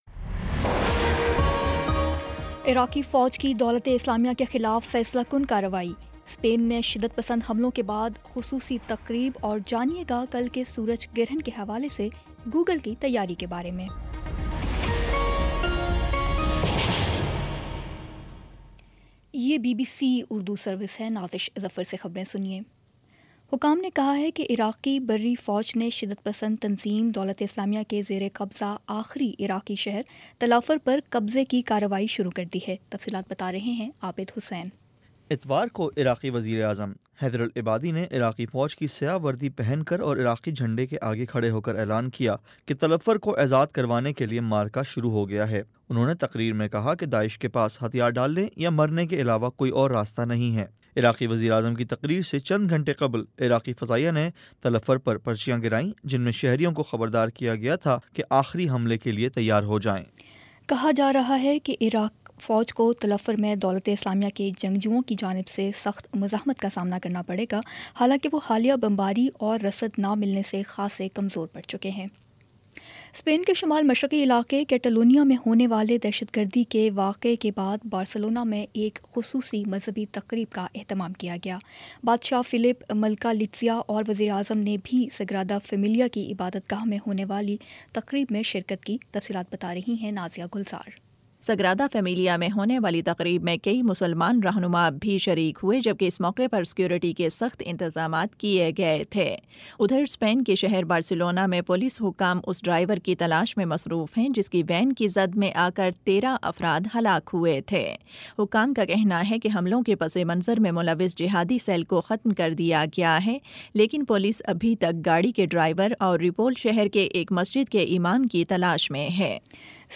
اگست20 : شام پانچ بجے کا نیوز بُلیٹن